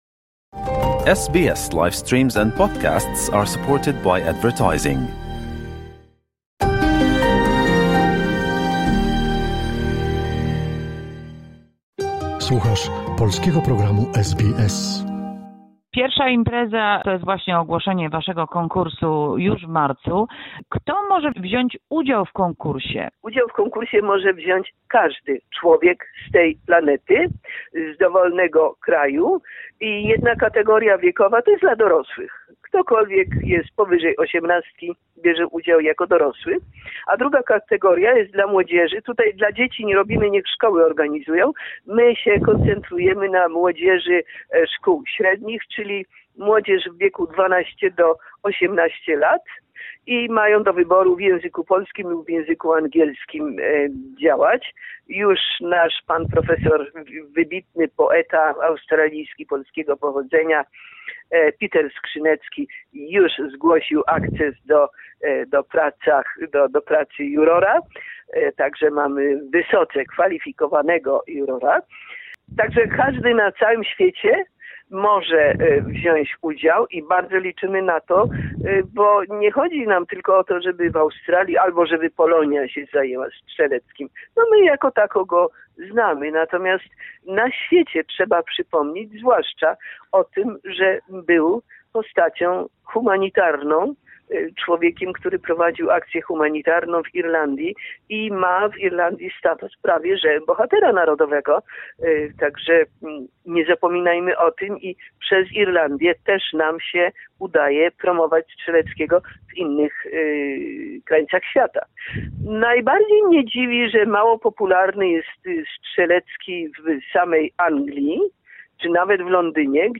Druga cz�� wywiadu (audycja pi�tkowa)